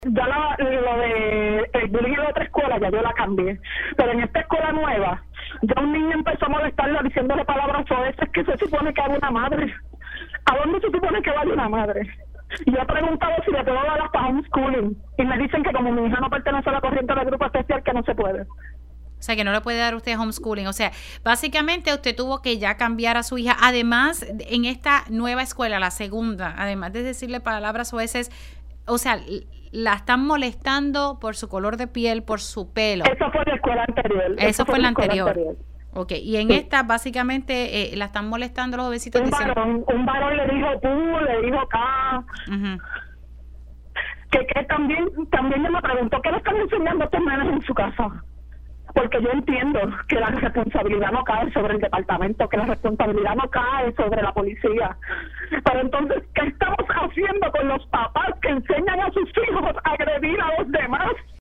Una madre, que decidió hablar en condición de anonimato en Pega’os en la Mañana, afirmó que en ambas escuelas de nivel intermedio en las que ha estado su hija, de 12 años, ha enfrentado acoso por parte de sus compañeros y compañeras, sin que ninguna autoridad tome acción.
Durante la entrevista y luego de denunciar que no ha habido ninguna investigación, personal de Educación se comunicó con la producción de Pega’os en la Mañana para atender el caso.